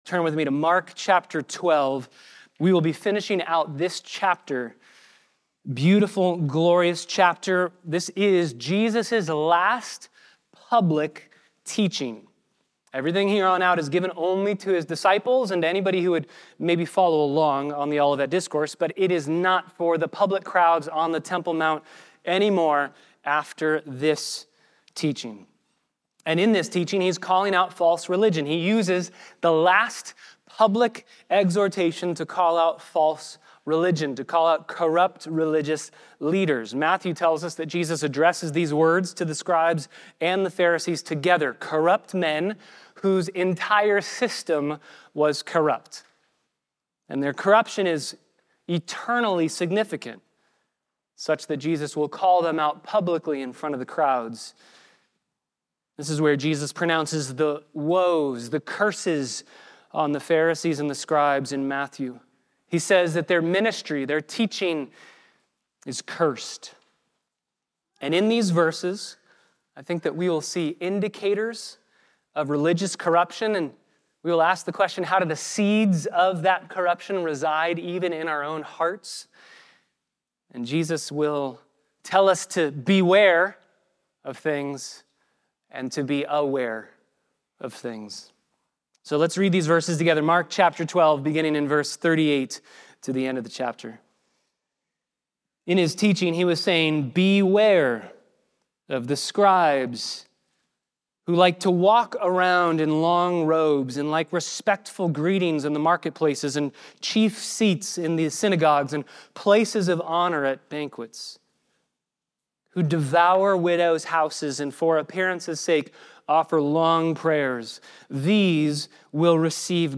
occasional guest speakers deliver God's Word at Christ Bible Church's weekly Sunday services.